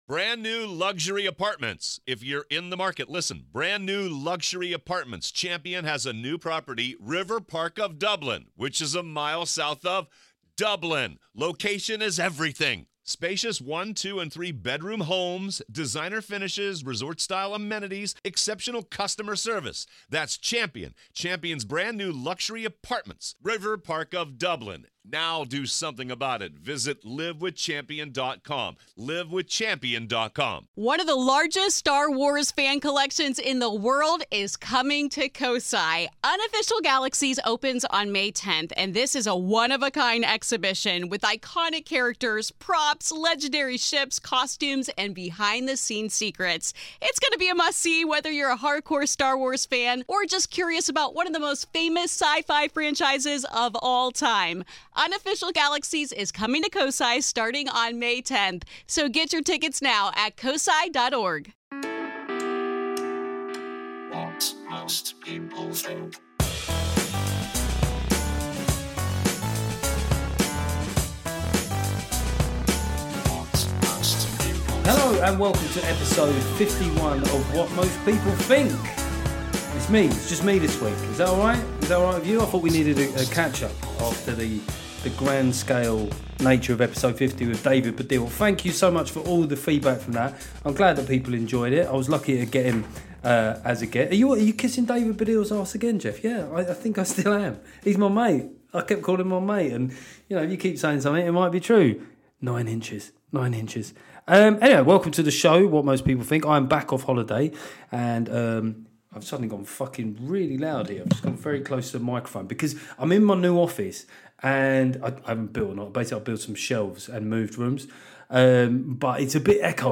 In this episode I talk fast, but promise I was not on hard drugs (sadly).
We also have a chat about satirical social media accounts like Titania Mcgrath getting frozen before a little bit of men's mental health. ps - I have now ordered a pop shield.